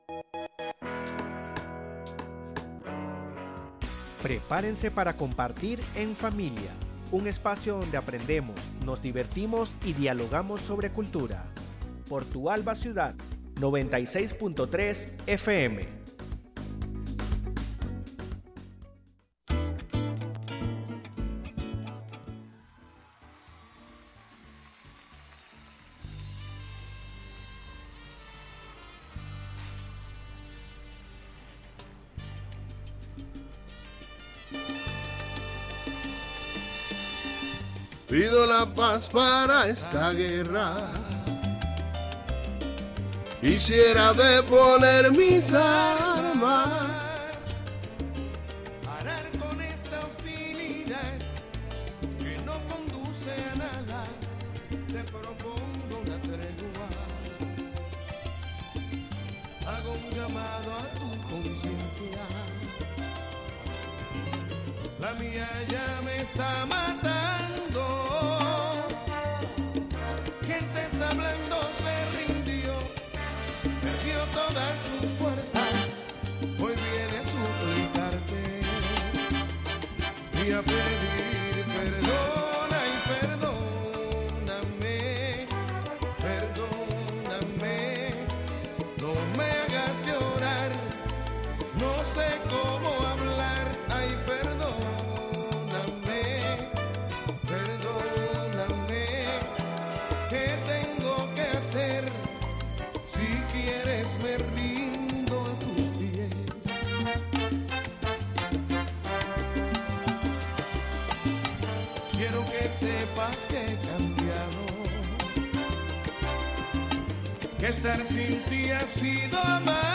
Programa emitido el 11/11/2024 a las 9:03 am.